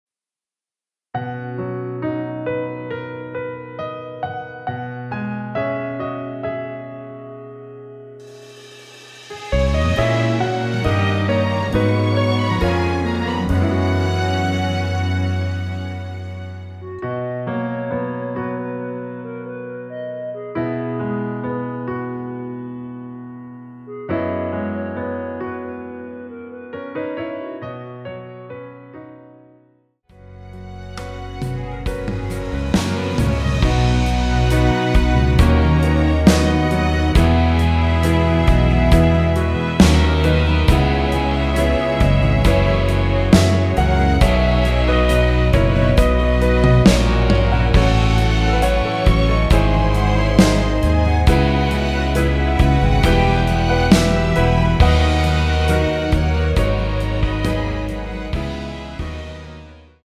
원키 멜로디 포함된 MR 입니다.(미리듣기 참조)
앞부분30초, 뒷부분30초씩 편집해서 올려 드리고 있습니다.
중간에 음이 끈어지고 다시 나오는 이유는